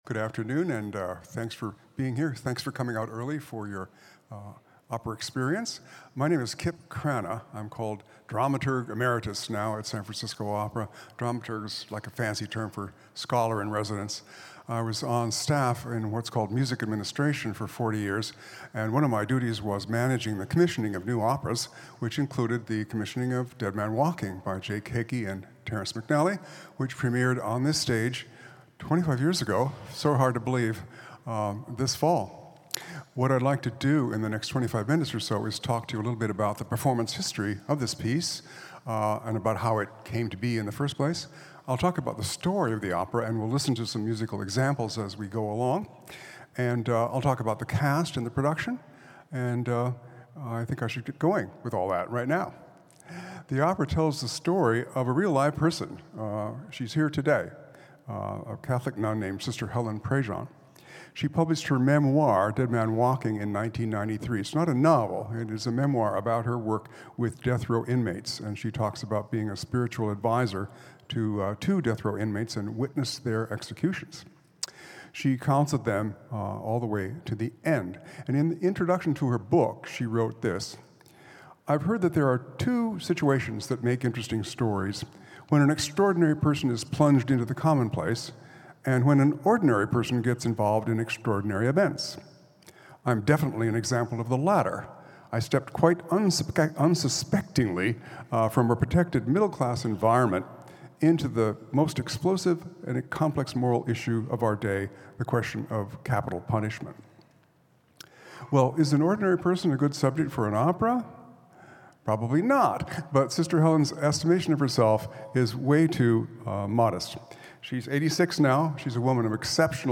Pre-opera talk